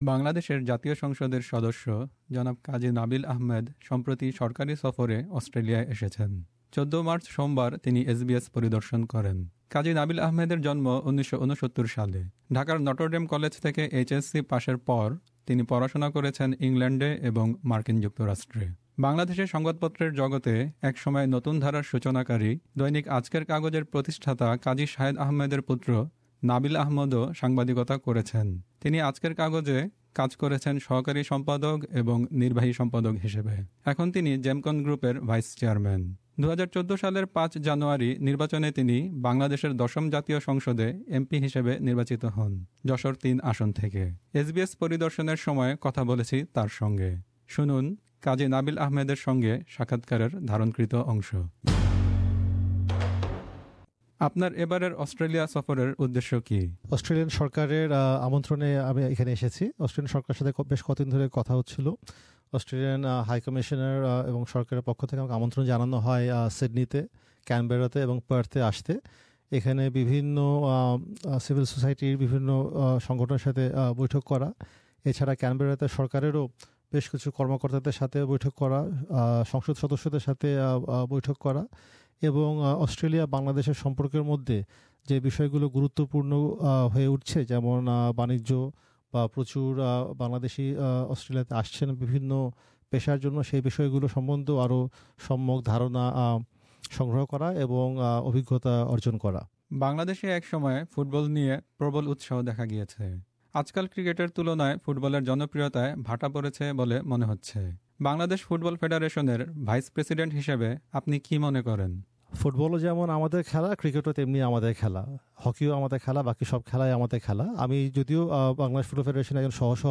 Interview with Kazi Nabil Ahmed